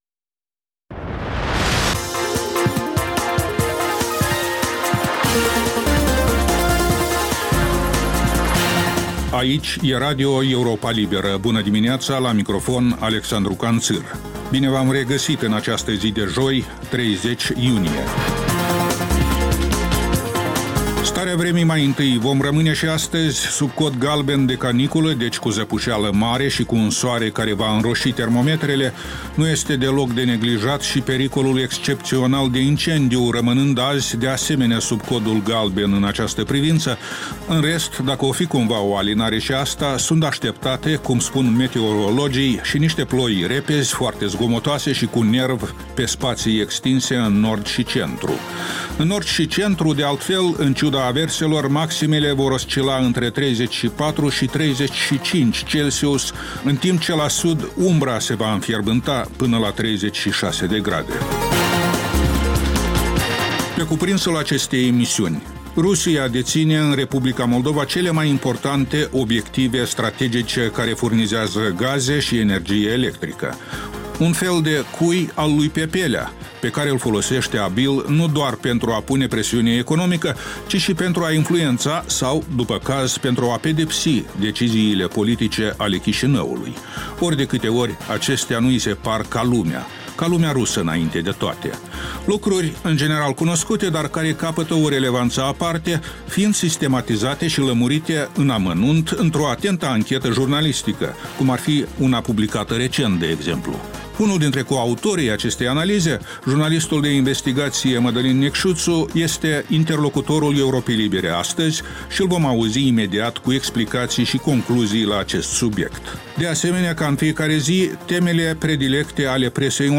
Ştiri, interviuri, analize. Programul care stabileşte agenda zilei.